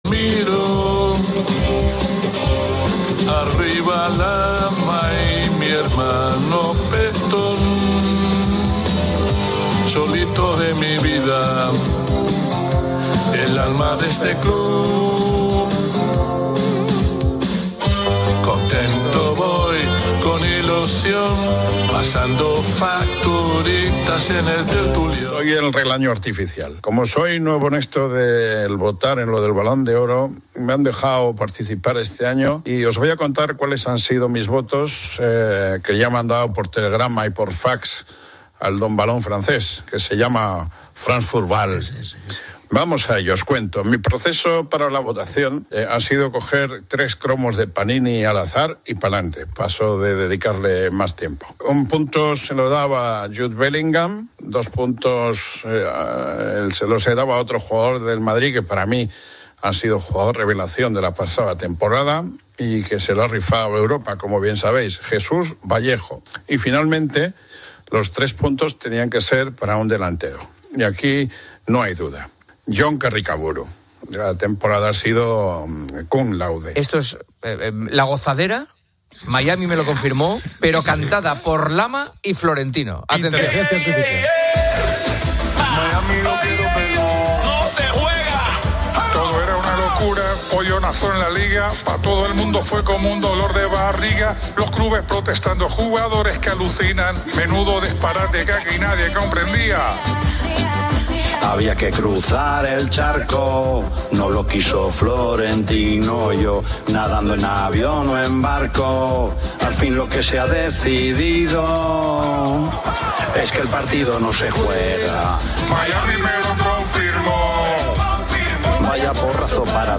El programa presenta varios segmentos de humor y parodia deportiva, incluyendo una votación satírica del Balón de Oro, llamadas de broma a un imitador de Carlos Sainz, y un rap con críticas a la actitud de Vinicius Jr. Además, se difunden cómicos mensajes de voz de famosos y se anuncia una peculiar lista de convocados para el Mundial. La parte central del espacio se adentra en la importancia de la preservación de semillas con la existencia de la "Bóveda del Fin del Mundo" en Noruega.